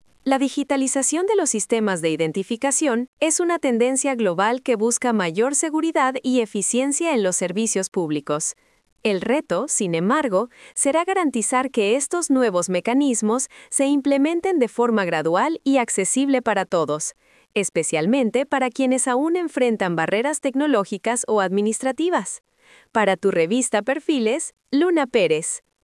🎙COMENTARIO EDITORIAL